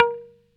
Index of /90_sSampleCDs/Roland LCDP02 Guitar and Bass/GTR_Dan Electro/GTR_Dan-O 6 Str